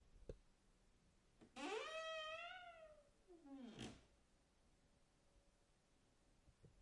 门吱吱作响
描述：门吱吱作响打开
标签： 咯吱
声道立体声